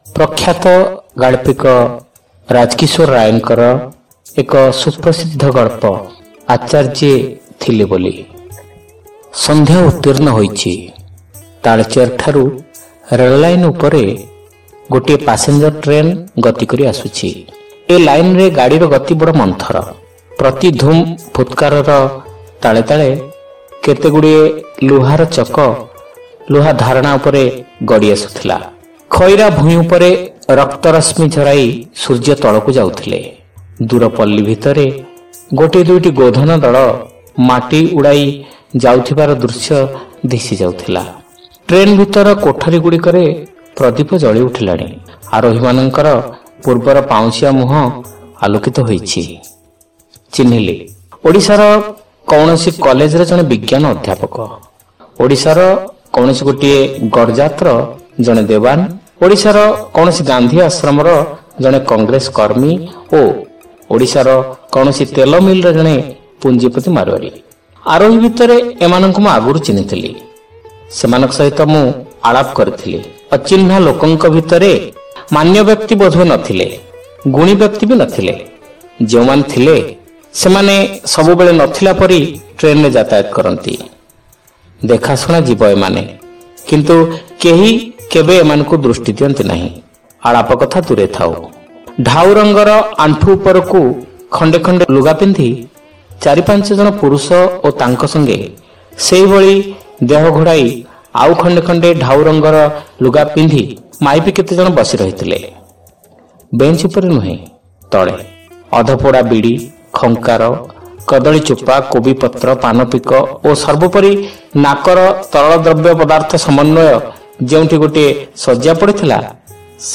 Audio Story : Acharjye Thile Boli